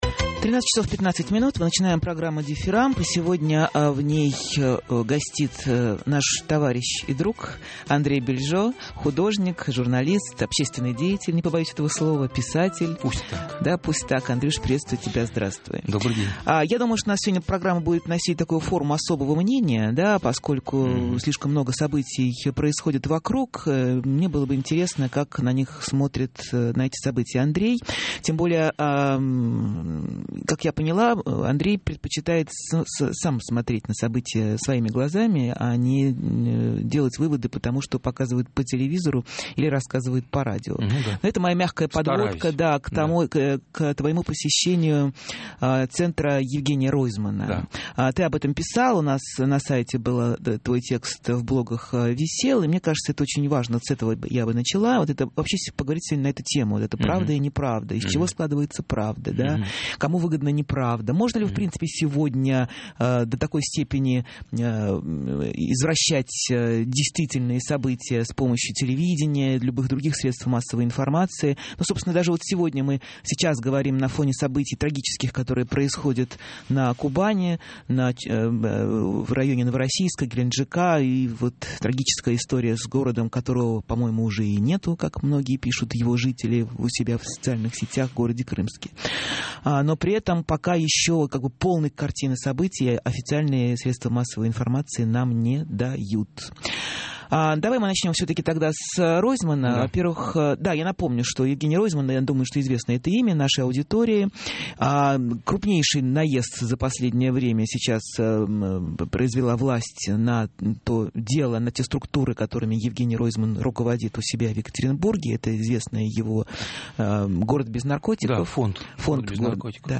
И сегодня в ней гостит наш товарищ и друг Андрей Бильжо, художник, журналист, общественный деятель, не побоюсь этого слова, писатель.